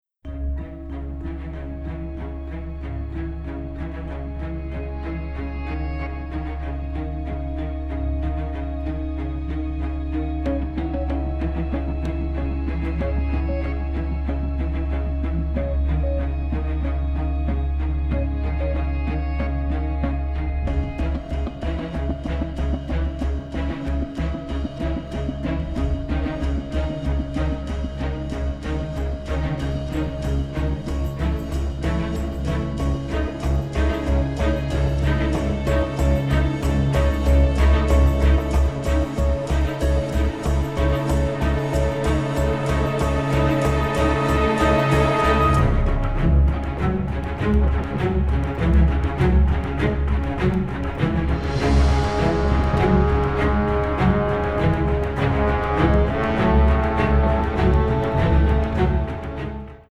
encompassing tenderness, sadness and nostalgia